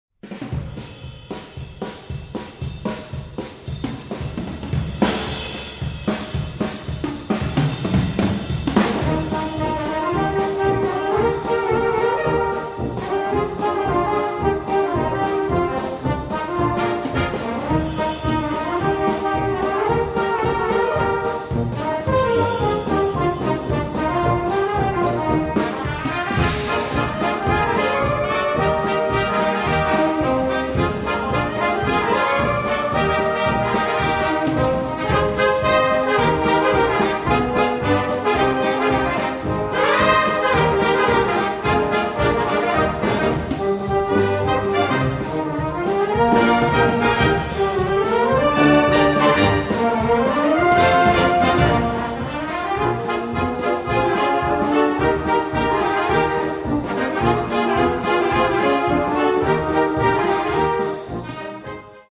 Een lekker swingend arrangement van deze populaire titel.